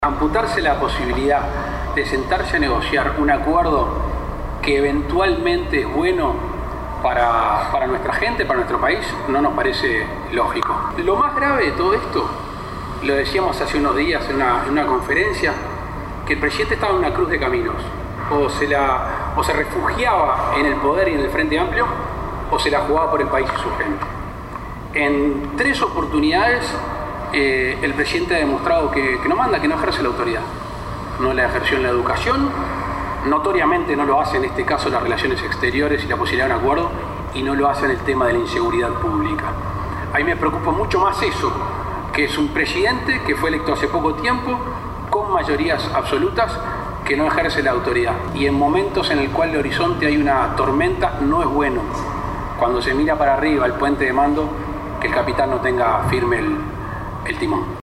En rueda de prensa, Lacalle Pou dijo que el Presidente Tabaré Vázquez no ejerce la autoridad y esto ha quedado demostrado en estos días en tres ocasiones.
Escuche a Lacalle Pou